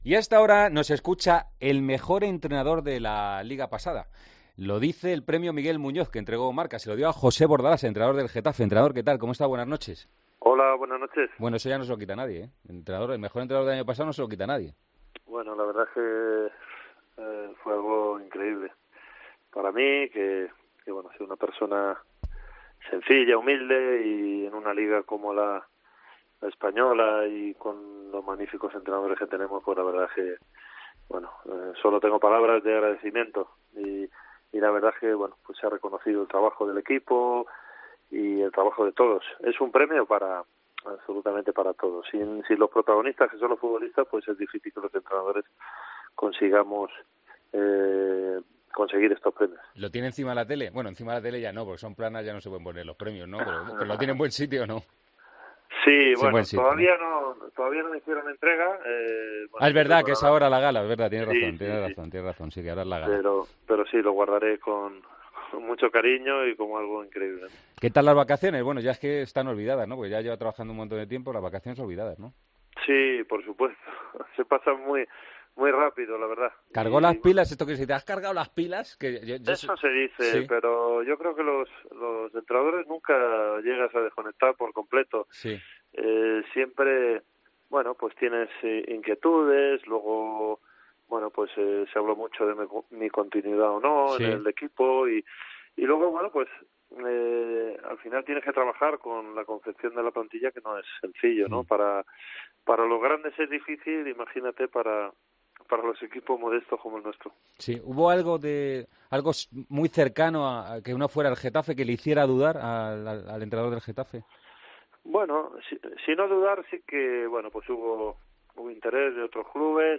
El entrenador del Getafe, José Bordalás, ha pasado este martes por los micrófonos de El Partidazo de COPE .